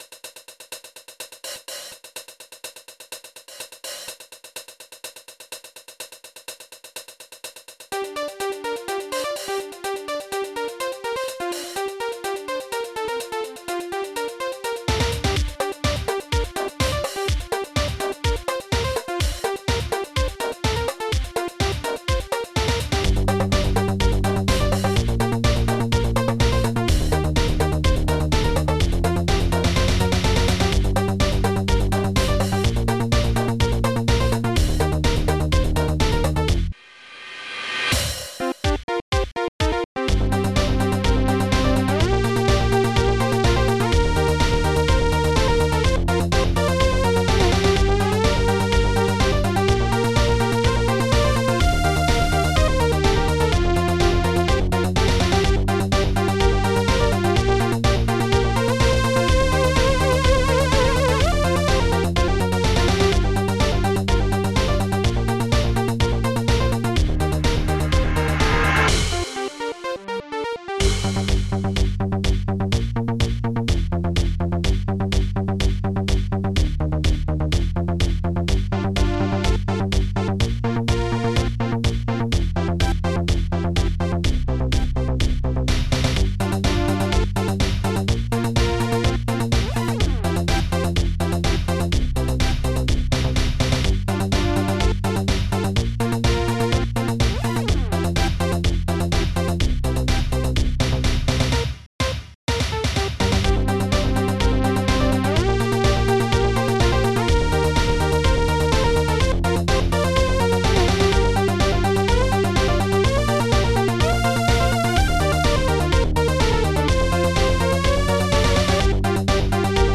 ST-07:conga3
ST-07:reversecymbal
ST-07:hihatcl5
ST-07:tom-cave1
ST-03:powerbassdrum1
ST-07:hihatop5
ST-06:bass-animate
ST-04:synthpiano
ST-02:lead6